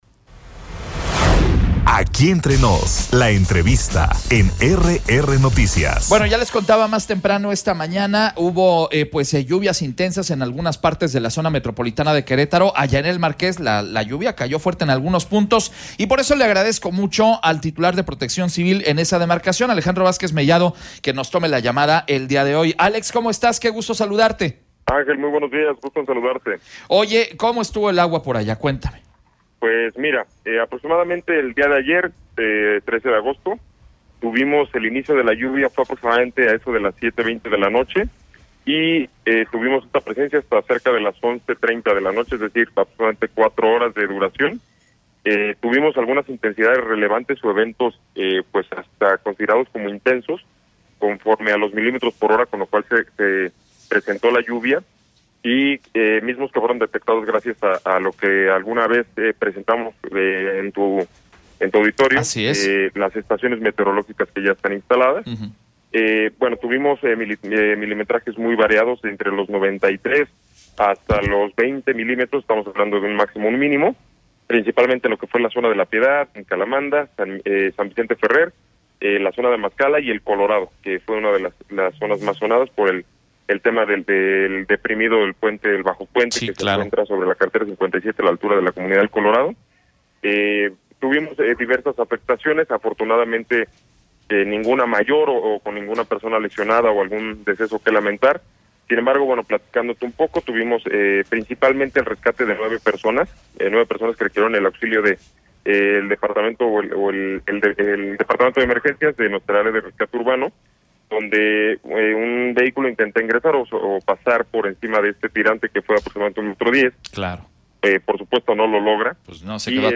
ENTREVISTA-DIR-PC-MARQUES-ALEJANDRO-VAZQUEZ-MELLADO.mp3